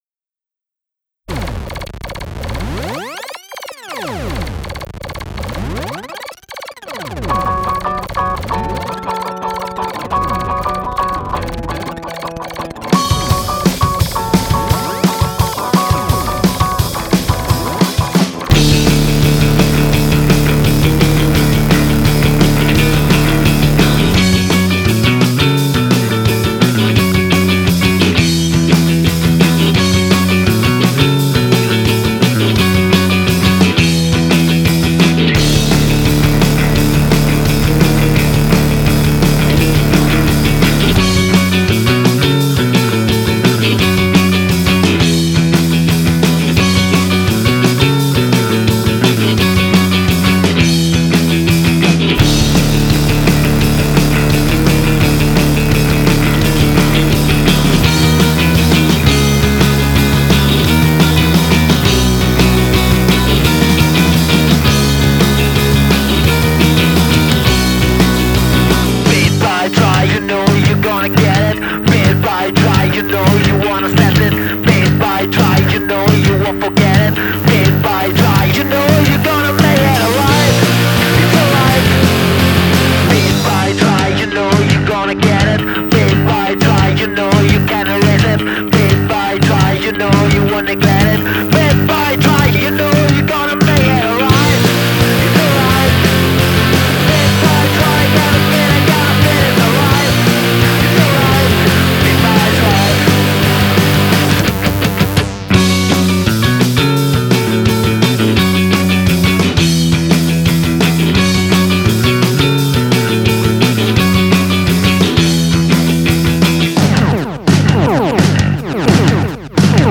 un quatuor d'androïdes mâles et femelles